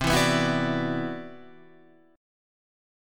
CM#11 chord